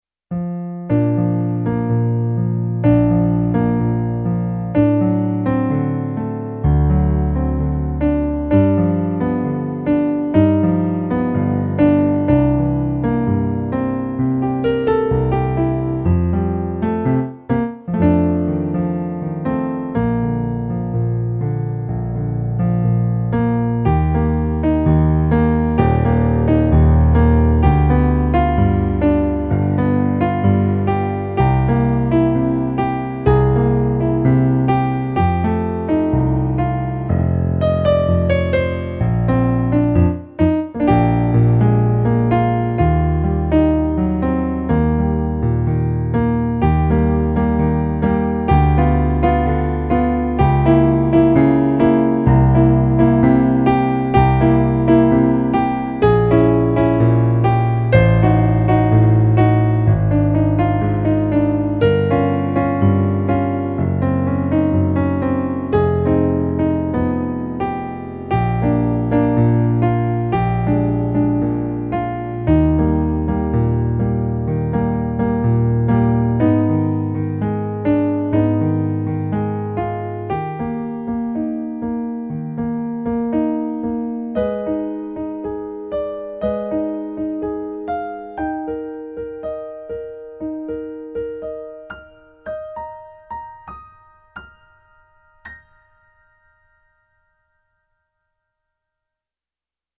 Latin ballad remix